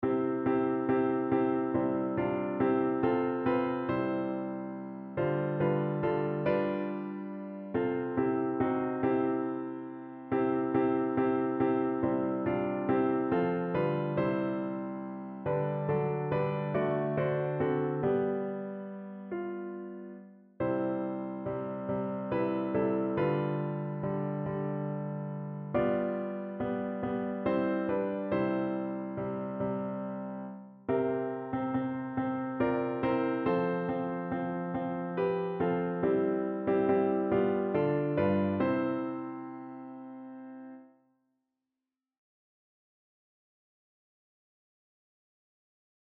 Evangeliumslieder